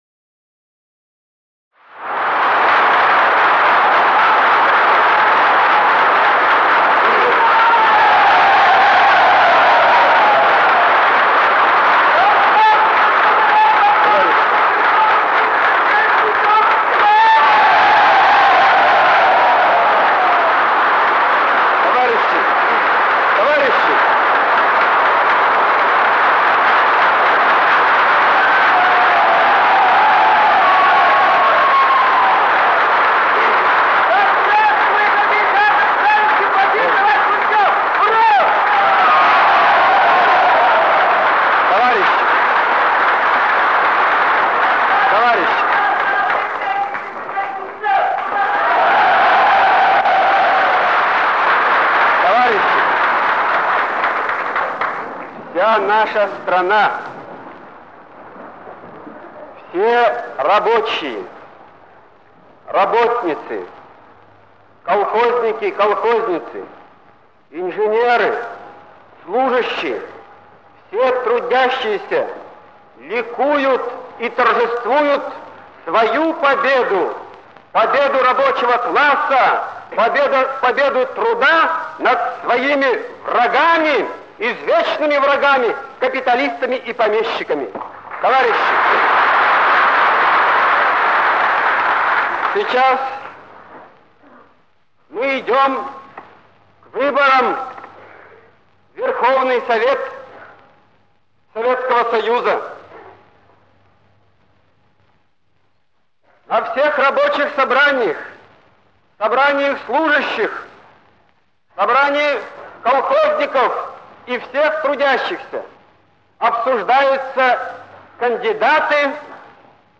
Речи